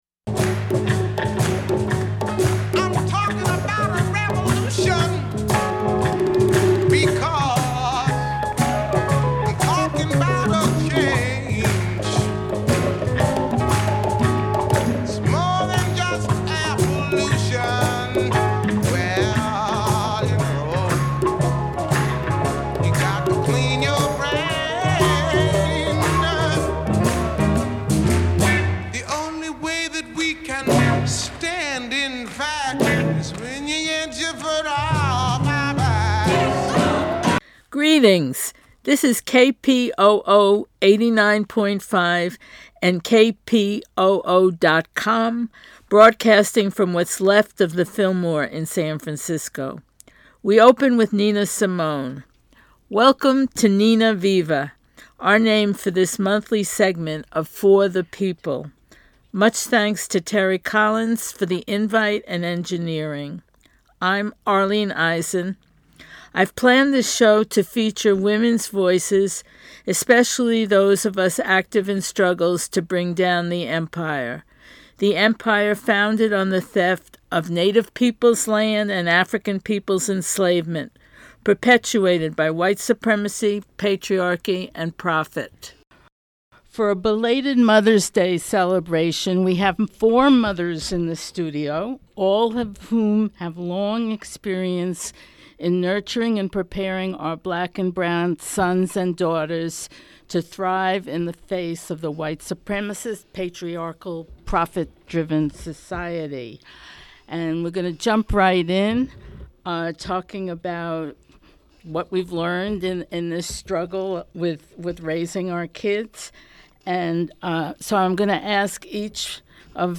Mothers-of-Black-Brown-Asian-kids-in-convo-May-2019.mp3